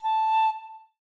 flute_a.ogg